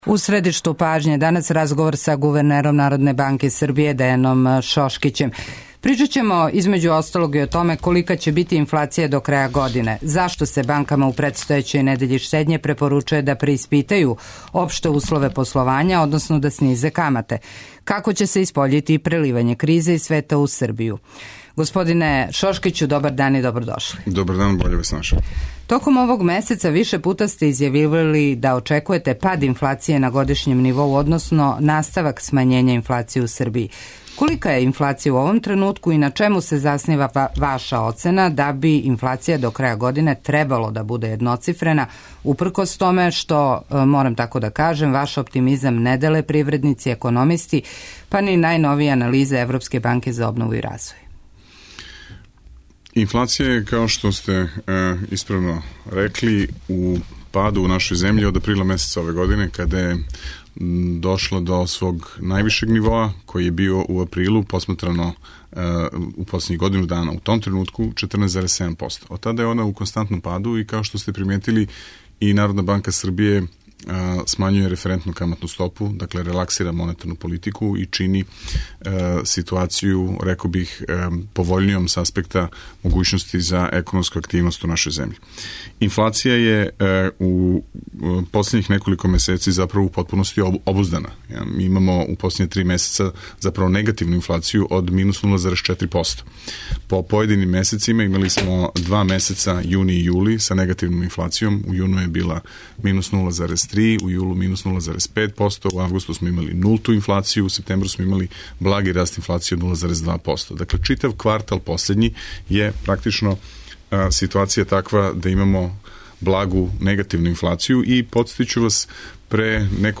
Гост емисије је гувернер Народне банке Србије Дејан Шошкић. Да ли је реалан оптимизам првог човека централне банке да ће инфлација до краја године бити једноцифрена а цене стабилне?